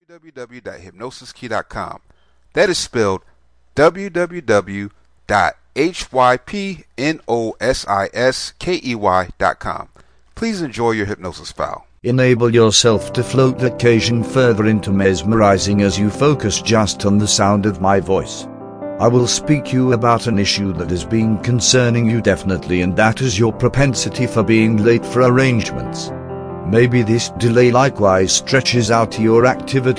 Punctuality Self Hypnosis Mp3